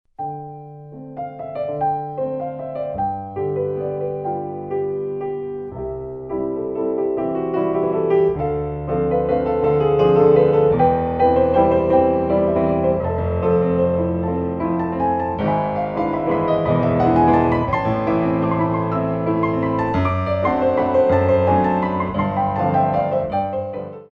Pirouettes